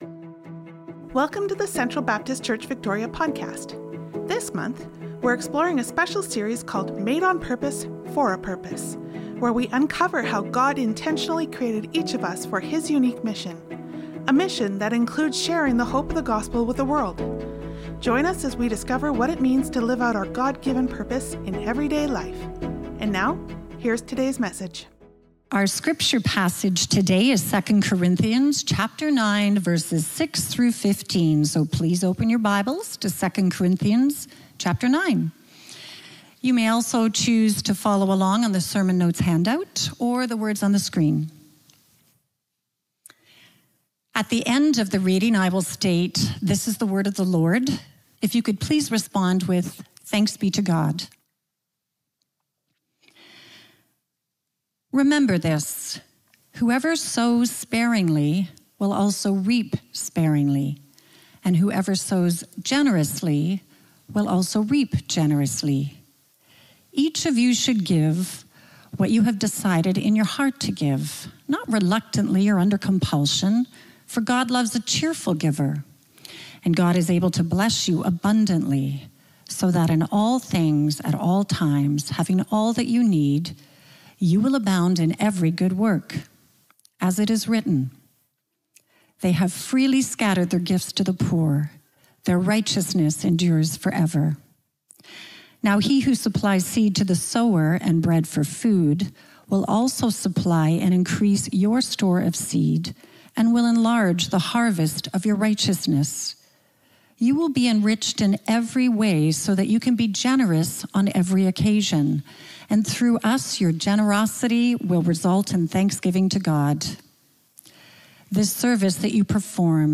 Sermons | Central Baptist Church
November 16, 2025 Guest Speaker Download Download Reference 2 Corinthians 9:6-15 Sermon Notes Nov 16'25.Worship Folder.pdf Nov 16'25.Sermon Notes.